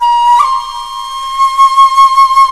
flute nș 2
flute2.mp3